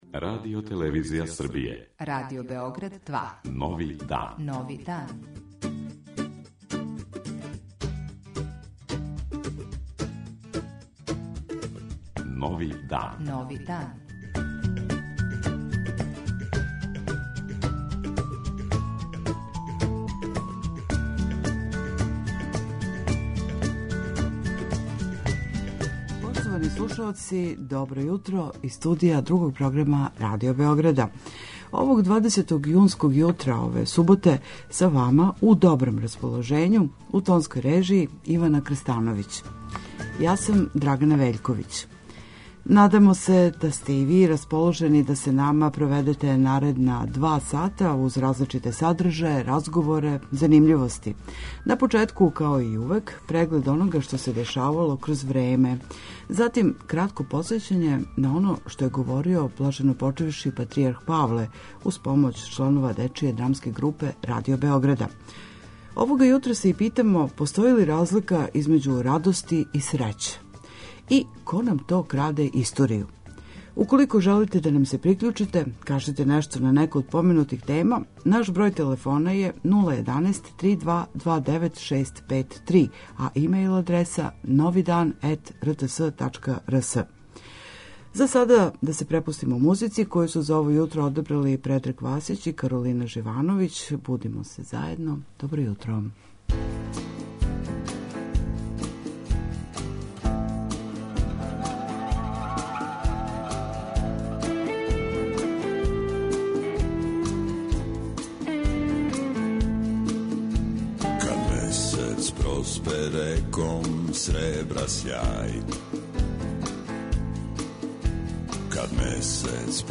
Јутарњи викенд програм магазинског типа